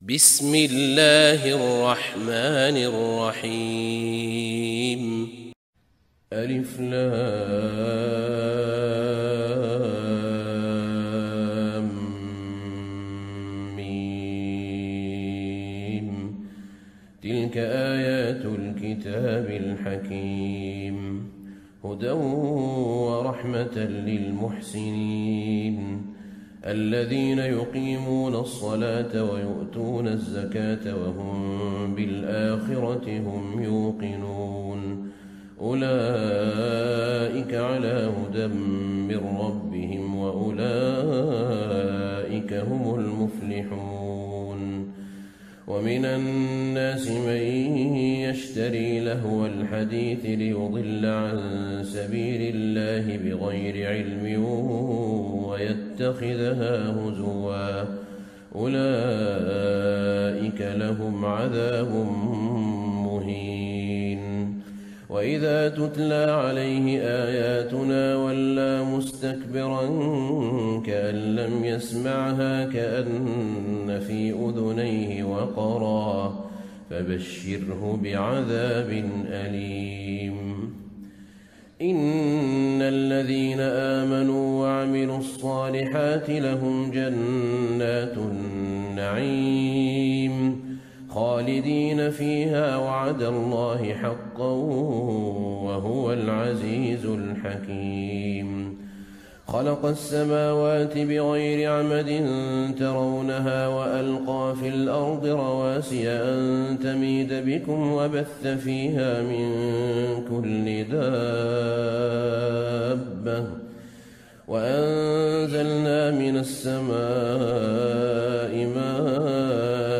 سورة لقمان > مصحف الشيخ أحمد بن طالب بن حميد من الحرم النبوي > المصحف - تلاوات الحرمين